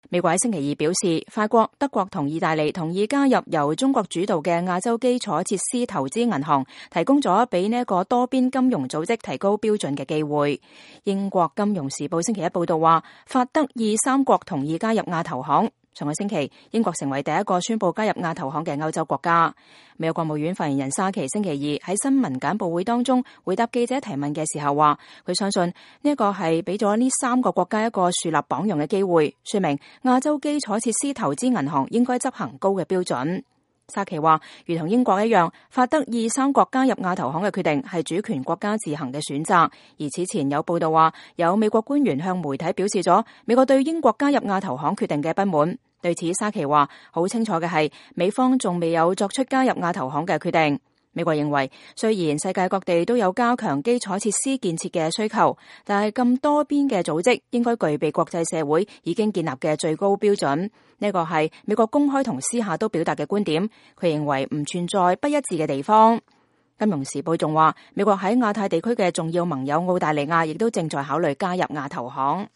美國國務院發言人莎琪星期二在新聞簡報時回答記者提問時說：“我想，這給了這三個國家一個樹立榜樣的機會，說明亞洲基礎設施投資銀行應該執行高標準。”